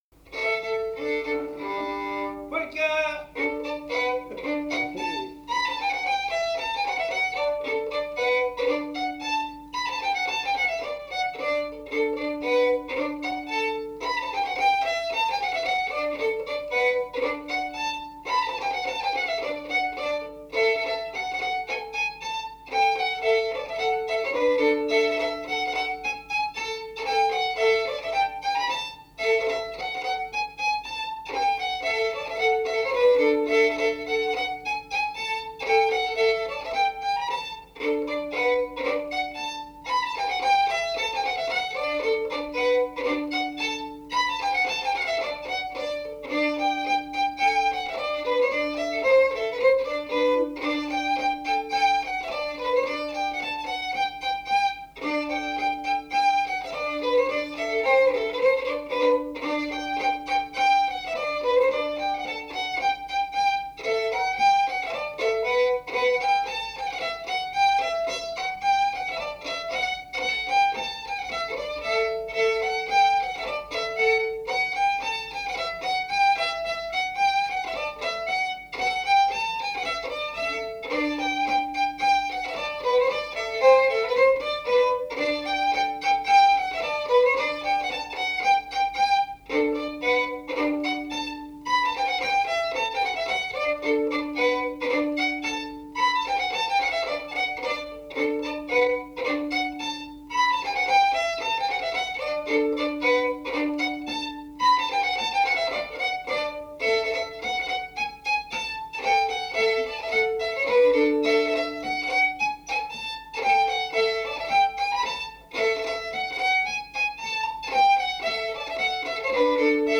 Mémoires et Patrimoines vivants - RaddO est une base de données d'archives iconographiques et sonores.
Polka
Résumé instrumental
danse : polka
Pièce musicale inédite